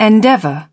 Транскрипция и произношение слова "endeavour" в британском и американском вариантах.